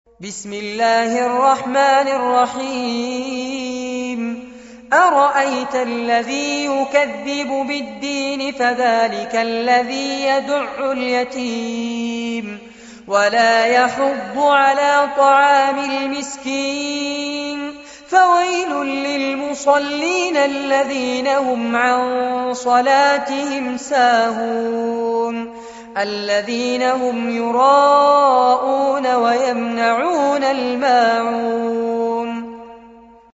عنوان المادة سورة الماعون- المصحف المرتل كاملاً لفضيلة الشيخ فارس عباد جودة عالية